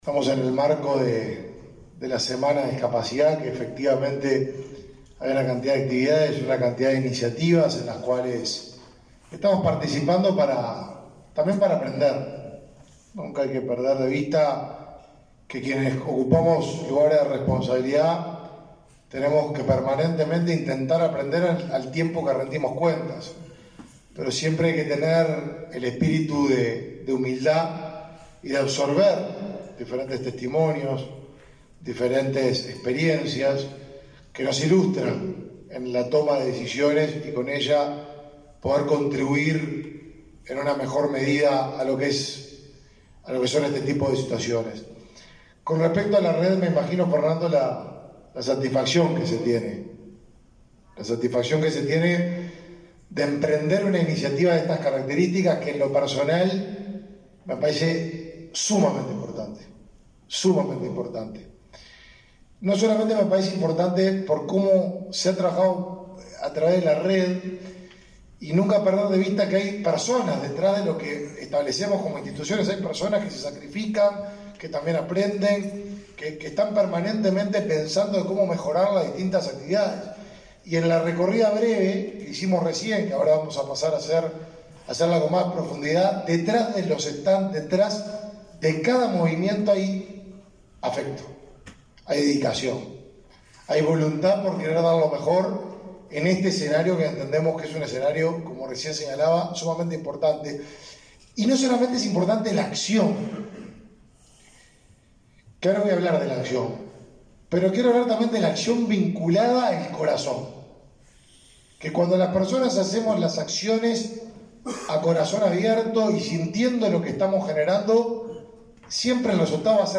Palabras del ministro de Desarrollo Social, Martín Lema
Palabras del ministro de Desarrollo Social, Martín Lema 01/12/2021 Compartir Facebook X Copiar enlace WhatsApp LinkedIn Este miércoles 1.° en el auditorio de Antel, el ministro de Desarrollo Social, Martín Lema, participó de la inauguración en la primera feria binacional de empleo para personas con discapacidad.